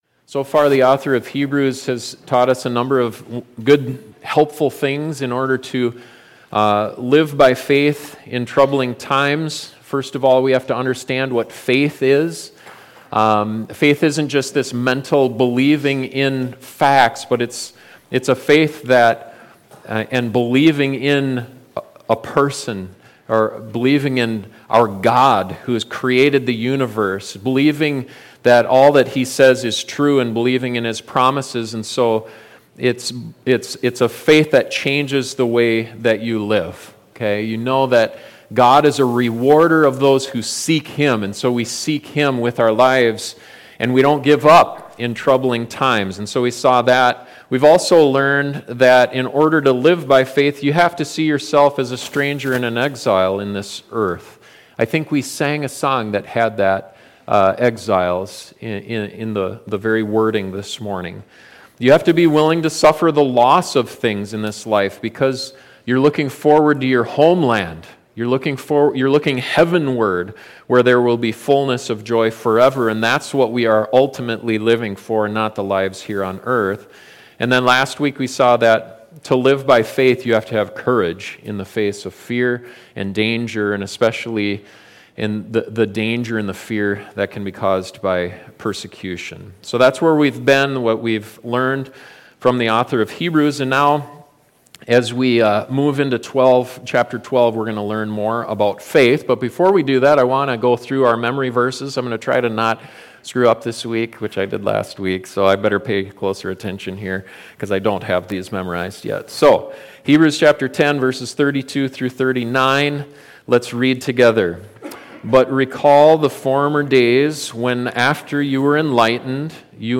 2025 The Need for Endurance Pastor